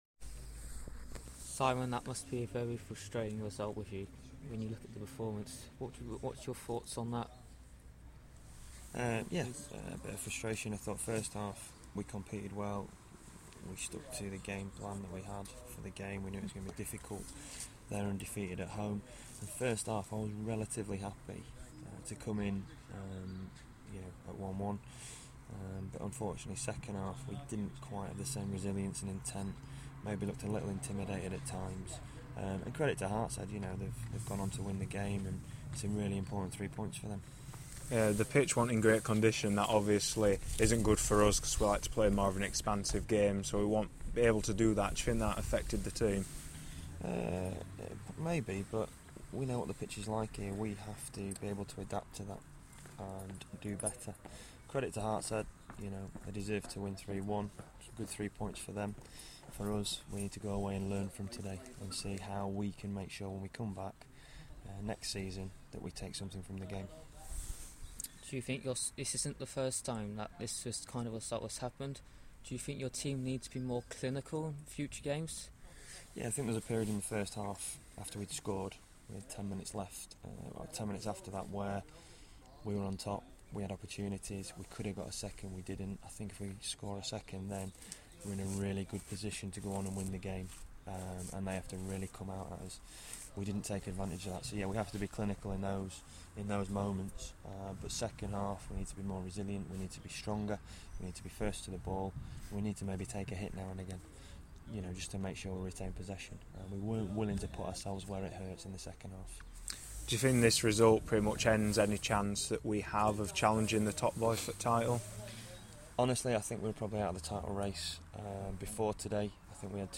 speaking after Ilkley's 3-1 loss to Hartshead.